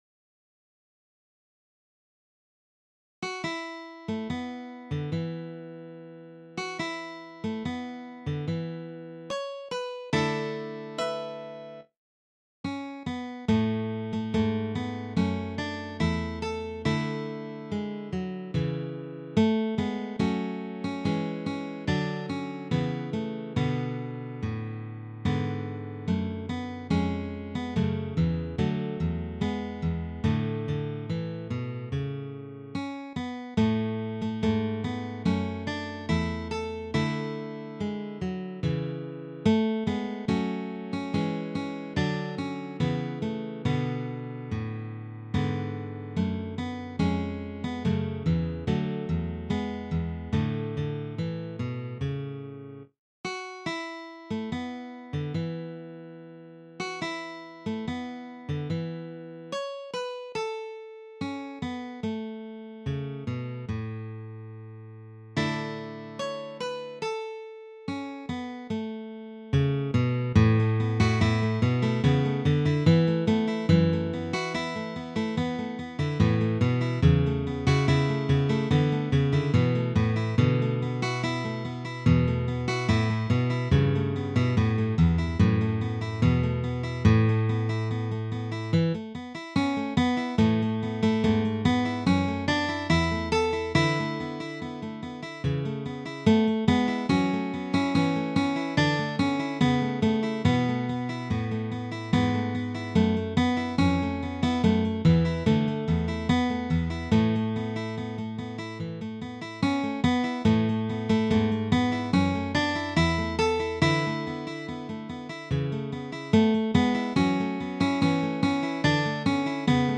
for three guitars
This is a traditional Catalan piece.